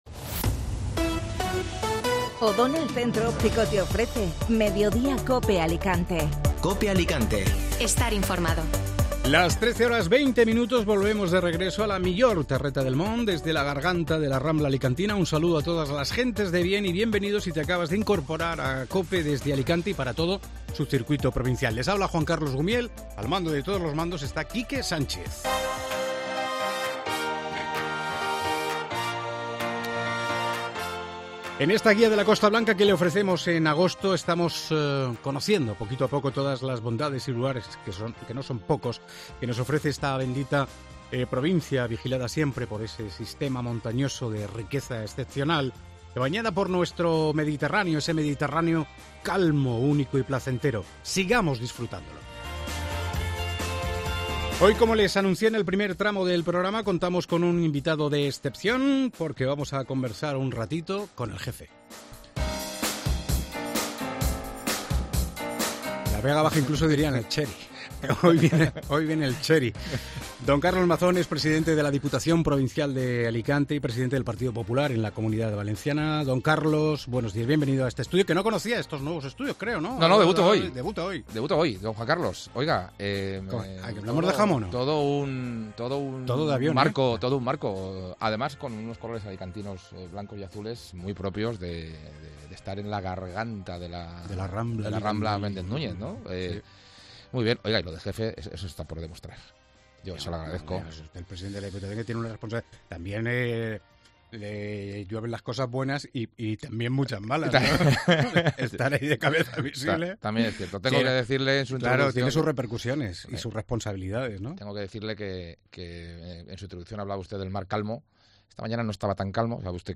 AUDIO: Escucha la entrevista al presidente de la Diputación de Alicante, Carlos Mazón